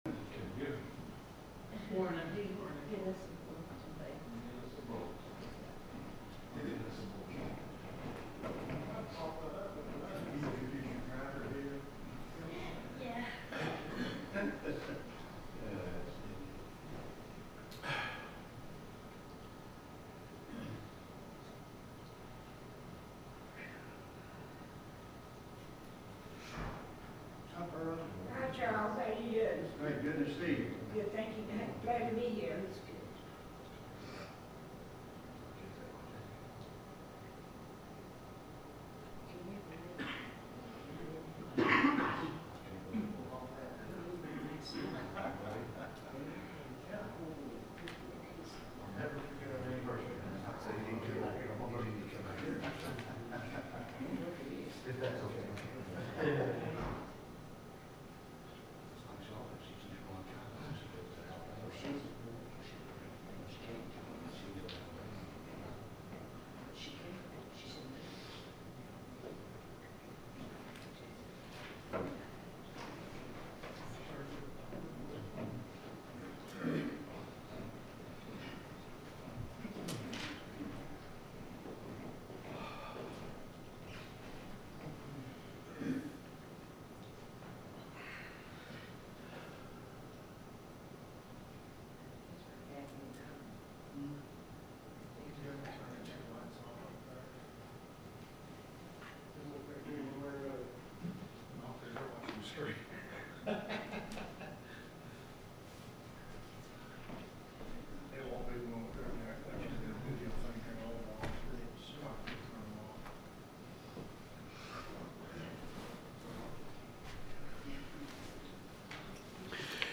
The sermon is from our live stream on 10/26/2025